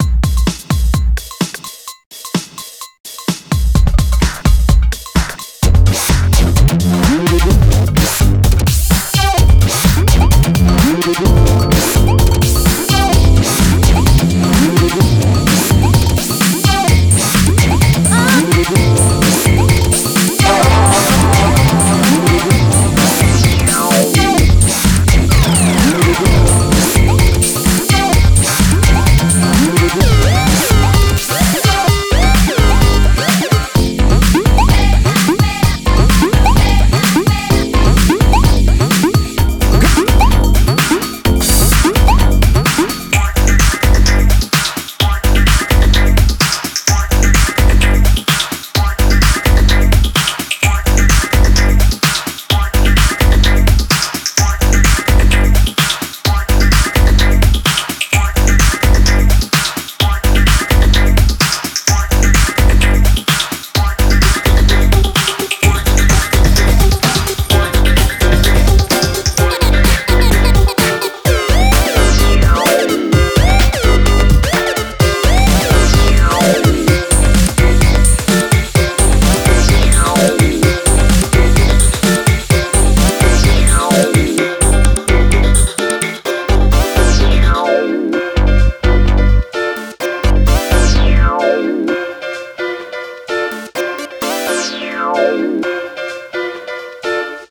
BPM64-128
Audio QualityMusic Cut